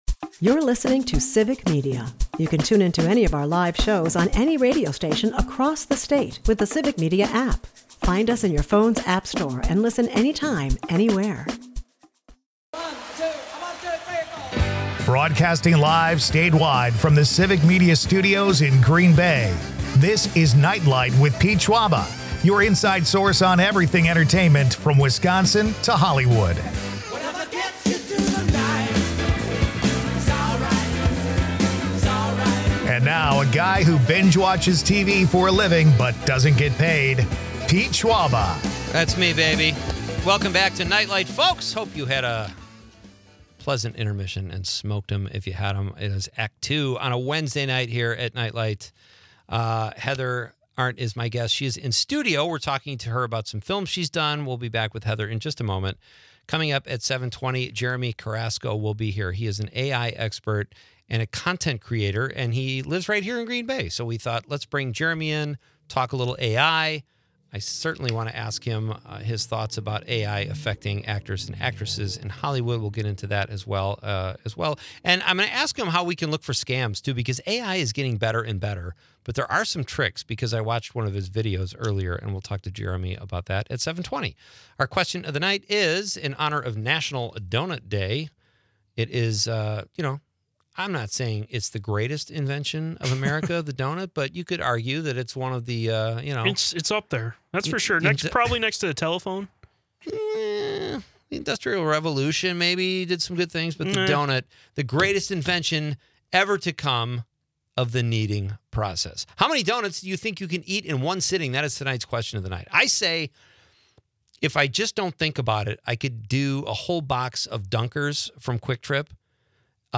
Meanwhile, listeners weigh in on the pressing question of how many donuts they can devour in one sitting, adding a sweet touch to the evening's discussions.